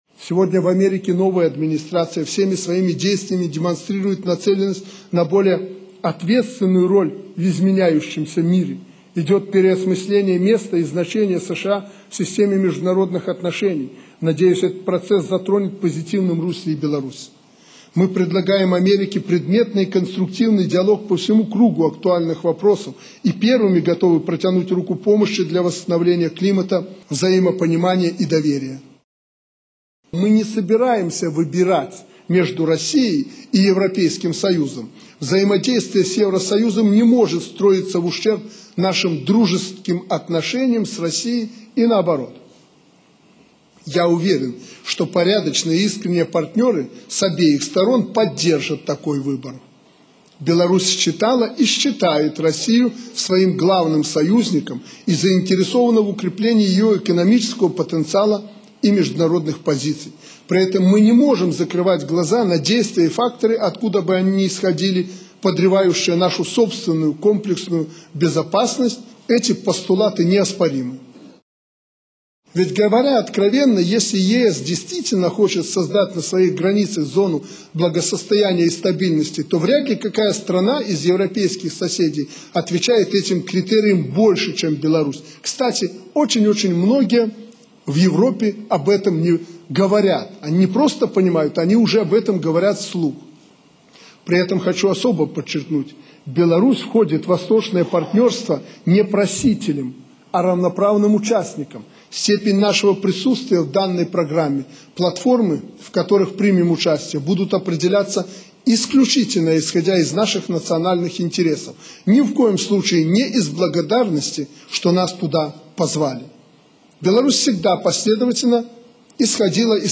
Фрагмэнты выступу Аляксандра Лукашэнкі